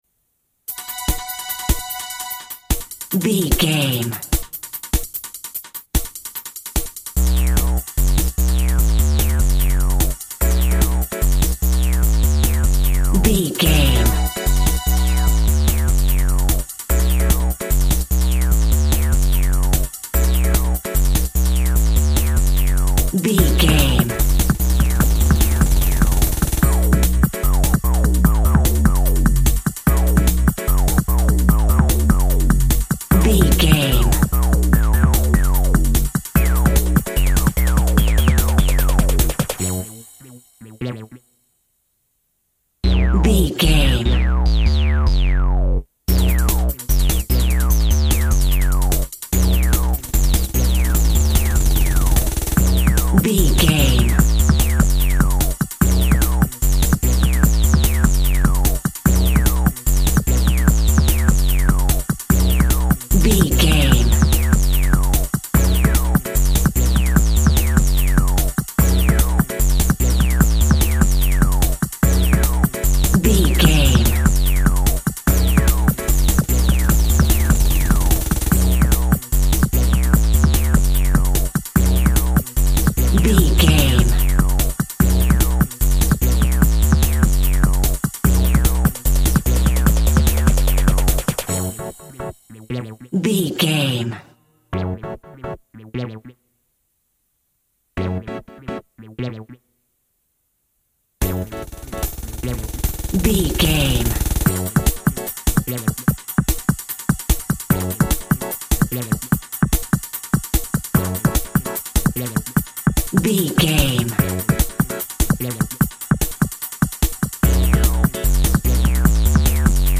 Drum & Bass Electronica.
Aeolian/Minor
Fast
futuristic
hypnotic
industrial
dreamy
frantic
drum machine
synthesiser
strings
electric piano
nu jazz
synth lead
synth bass